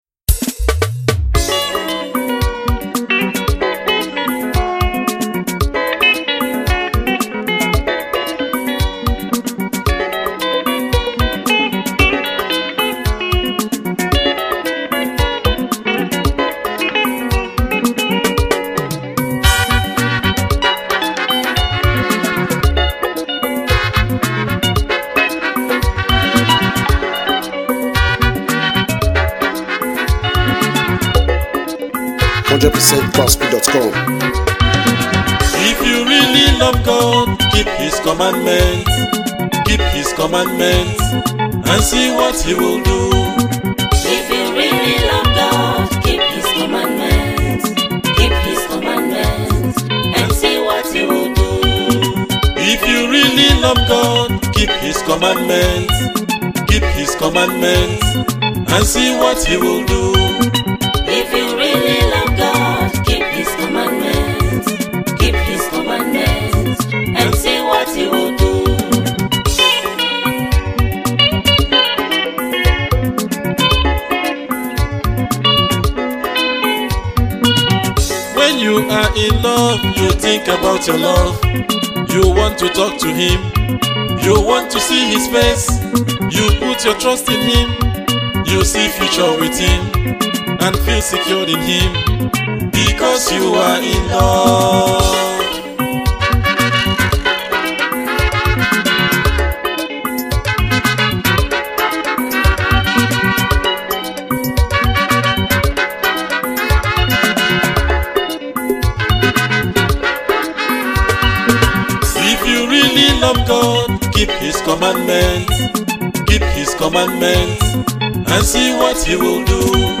Gospel Highlife